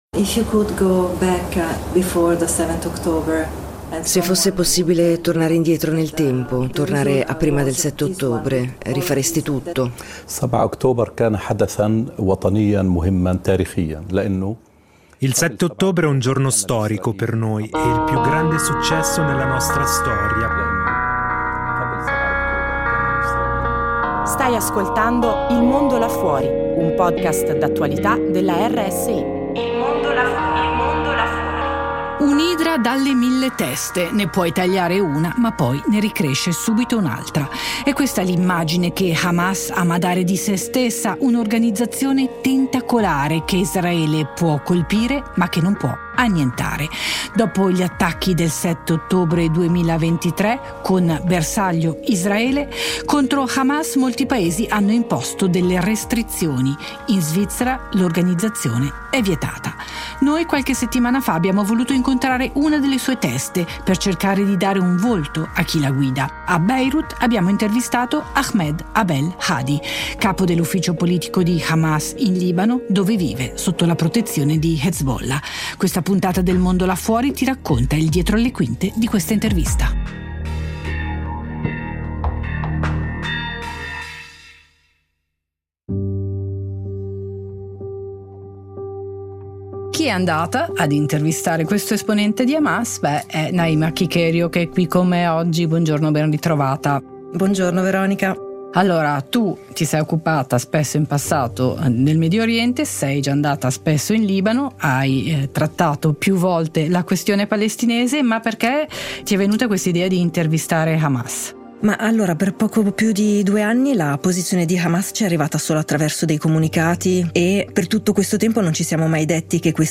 Il dietro le quinte dell’intervista con uno dei leader dell’organizzazione terroristica